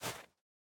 Minecraft Version Minecraft Version latest Latest Release | Latest Snapshot latest / assets / minecraft / sounds / block / powder_snow / break1.ogg Compare With Compare With Latest Release | Latest Snapshot
break1.ogg